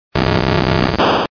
Fichier:Cri 0093 DP.ogg